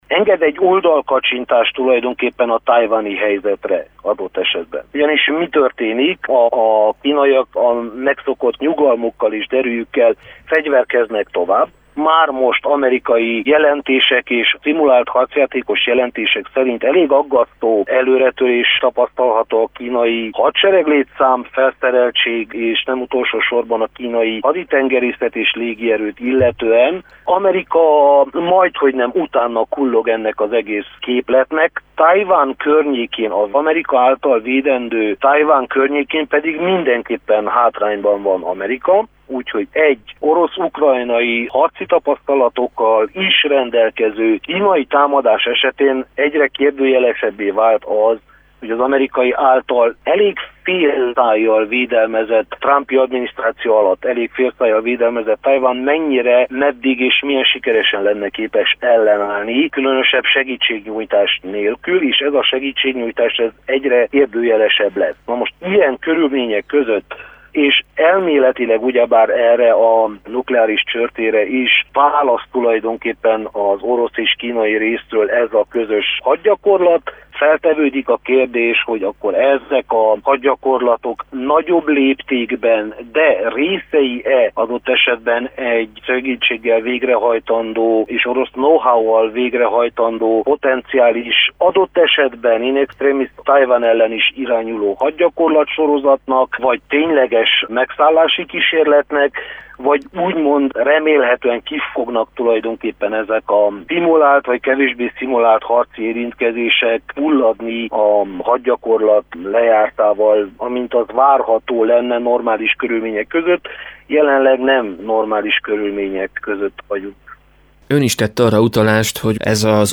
külpolitikai szakértőt